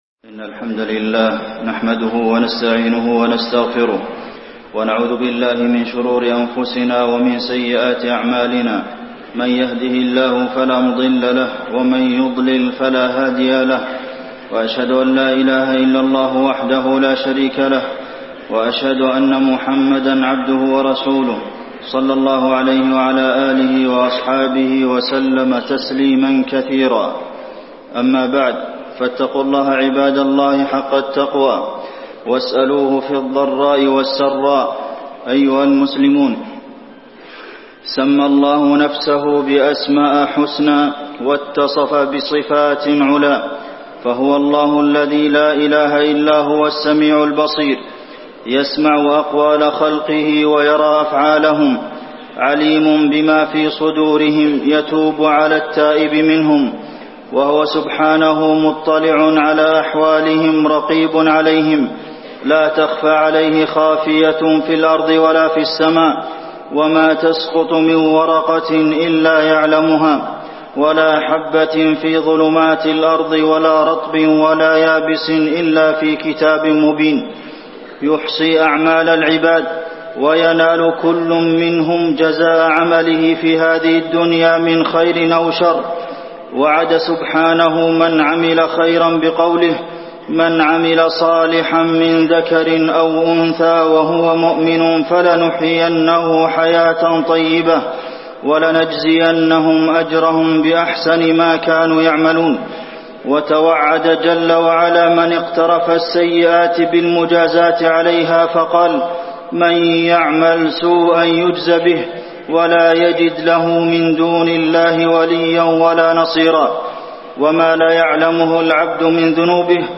خطبة الاستسقاء - المدينة- الشيخ عبدالمحسن القاسم
تاريخ النشر ٧ محرم ١٤٣٢ هـ المكان: المسجد النبوي الشيخ: فضيلة الشيخ د. عبدالمحسن بن محمد القاسم فضيلة الشيخ د. عبدالمحسن بن محمد القاسم خطبة الاستسقاء - المدينة- الشيخ عبدالمحسن القاسم